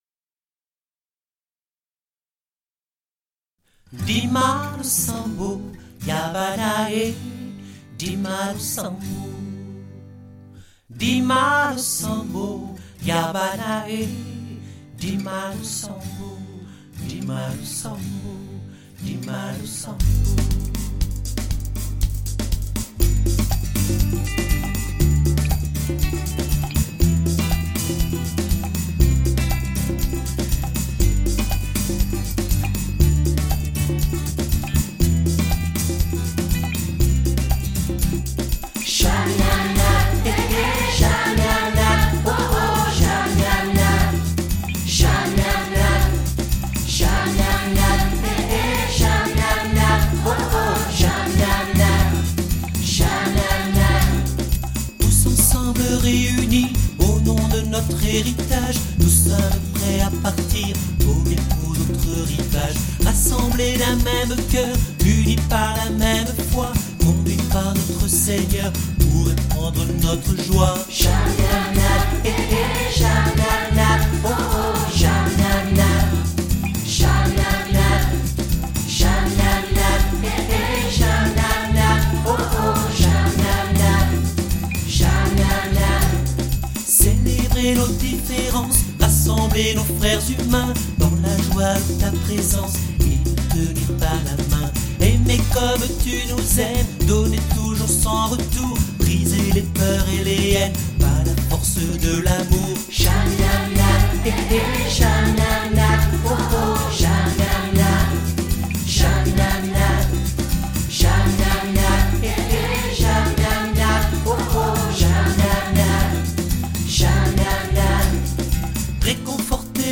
Souvenir du rassemblement du réseau éducatif à Lourdes